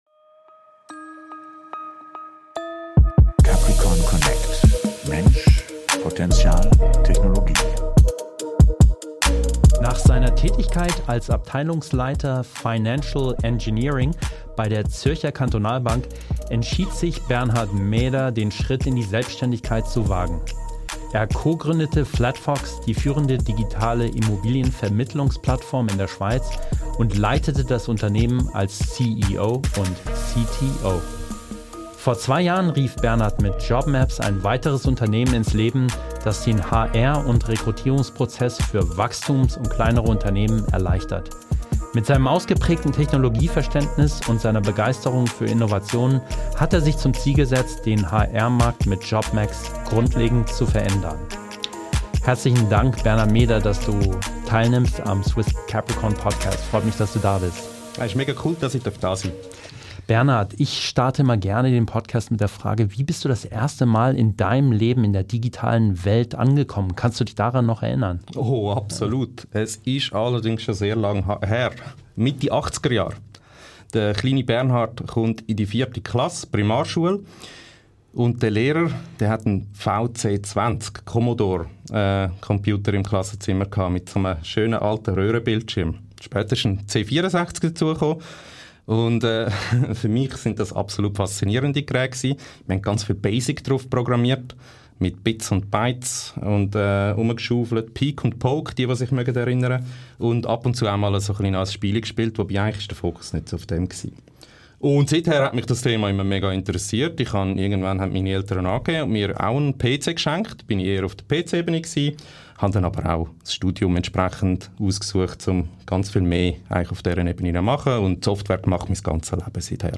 #61 - Interview